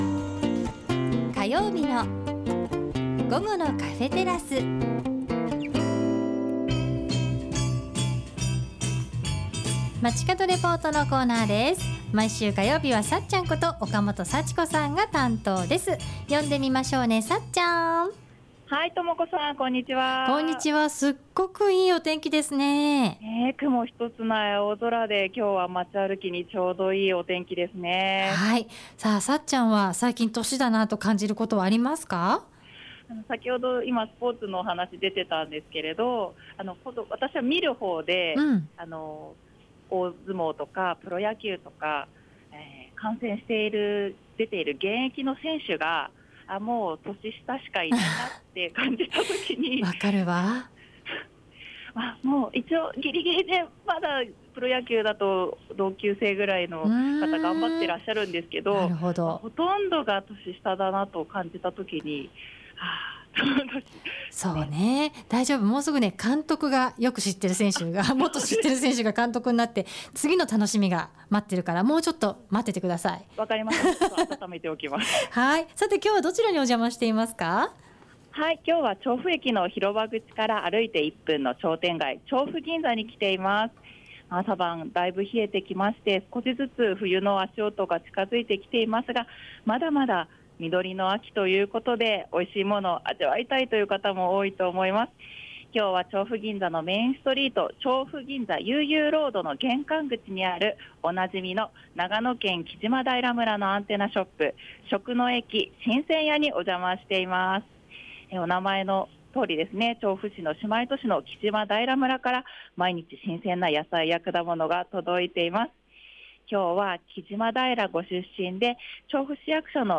街角レポート
ということで 中継は調布銀座のメインストリート「調布銀座ゆうゆうロード」の玄関口にある 長野県木島平村のアンテナショップ 「調布＆木島平 食の駅 新鮮屋」 にお邪魔しました。